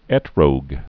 (ĕtrōg, -rəg, ĕt-rôg)